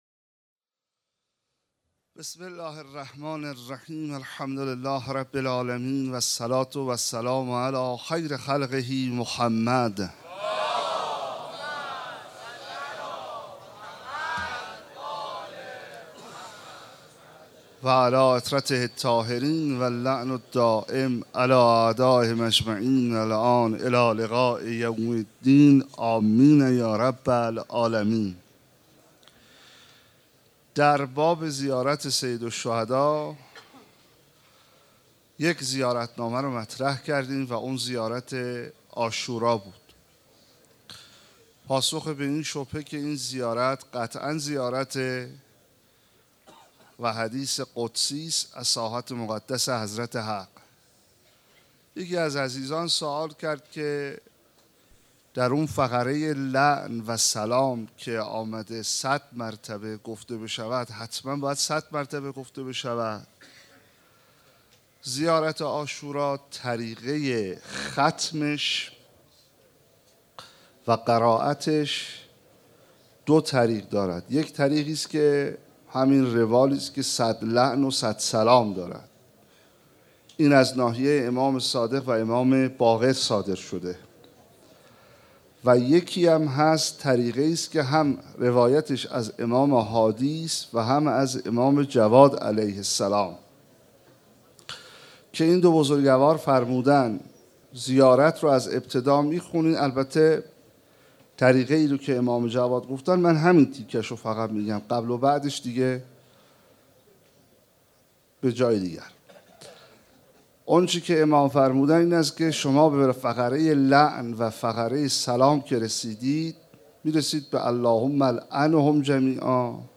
شب تاسوعا محرم 1436 - هیات رایه العباس B > سخنرانی